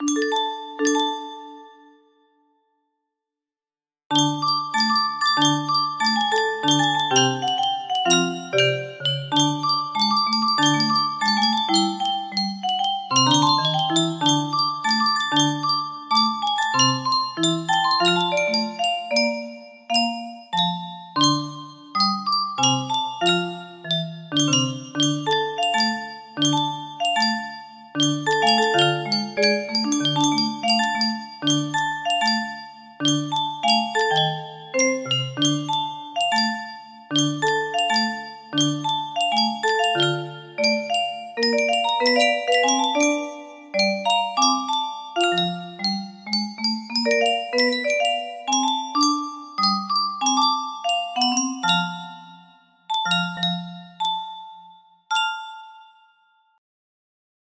Full range 60
As A Music Box.